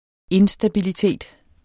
Udtale [ ˈensdabiliˌteˀd ]